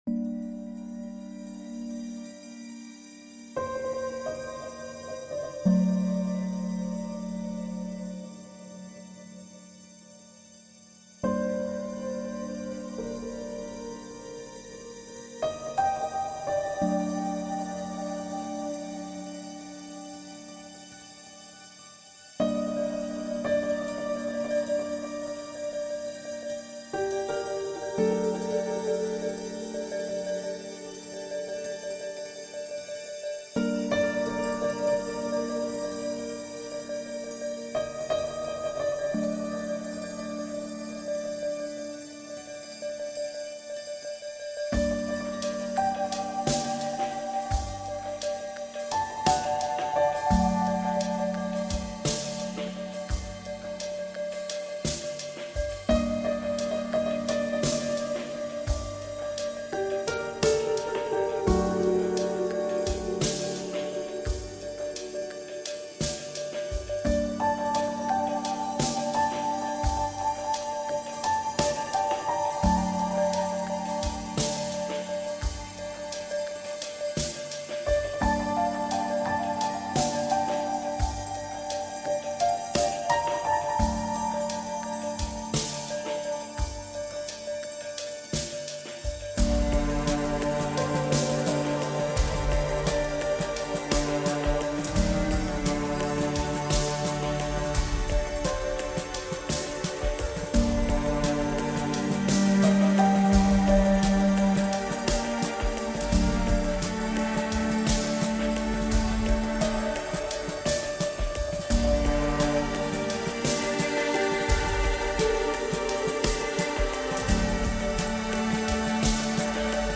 church chimes reimagined